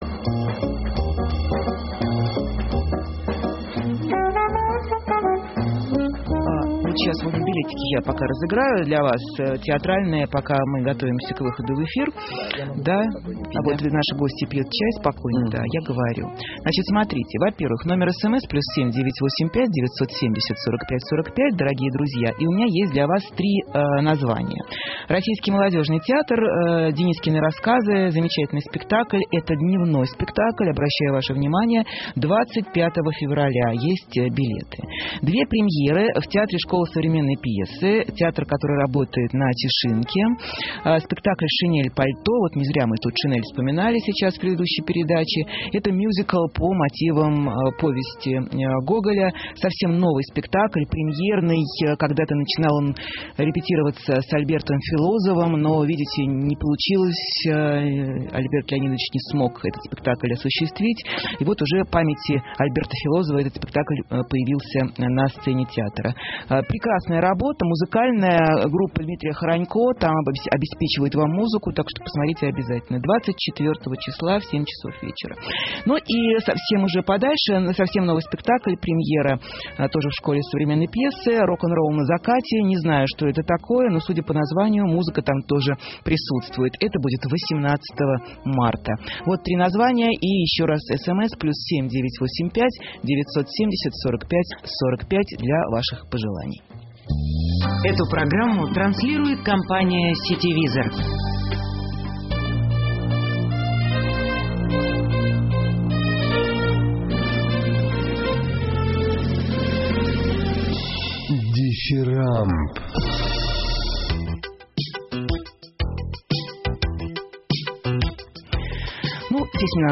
Интервью из архива «Эха Москвы»: «Дифирамб» с Верой Алентовой